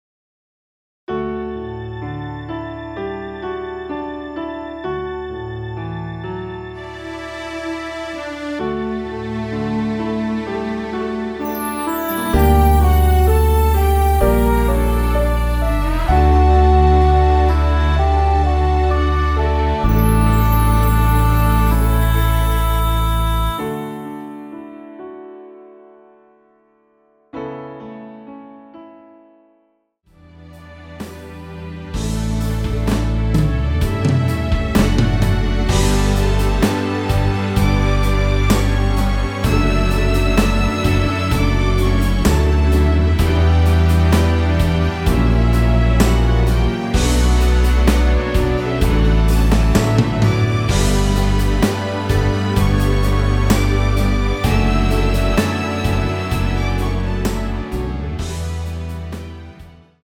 원키에서(-1)내린 MR입니다.
D
앞부분30초, 뒷부분30초씩 편집해서 올려 드리고 있습니다.
중간에 음이 끈어지고 다시 나오는 이유는